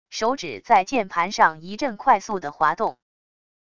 手指在键盘上一阵快速的滑动wav音频